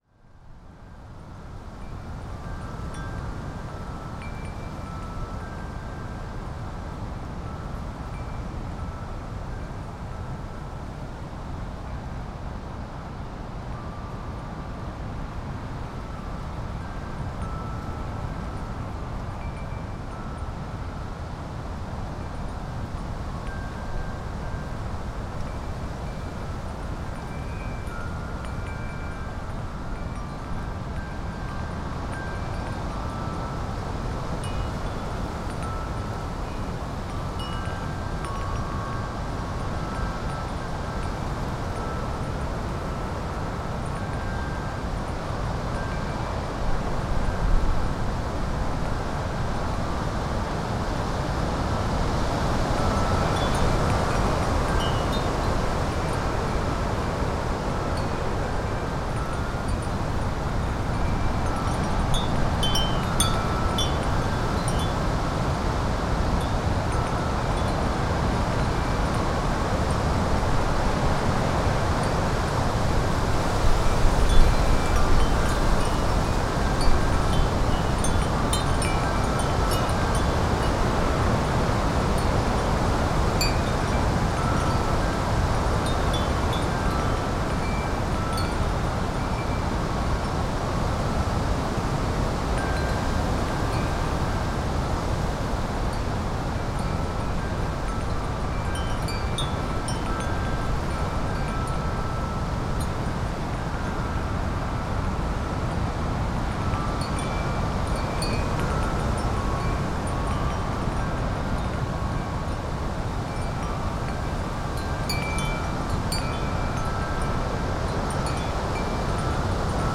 Wind Chimes in GALE over wooded valley - Gypsy Soprano - excerpt
chimes Devon Drewsteignton England field-recording gale gusts Gypsy sound effect free sound royalty free Nature